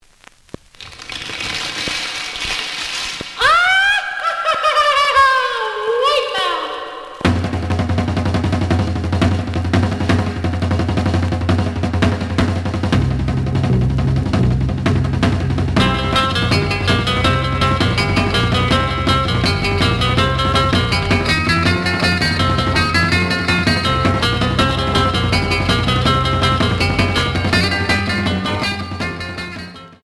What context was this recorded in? The audio sample is recorded from the actual item. Some click noise on beginning of both sides.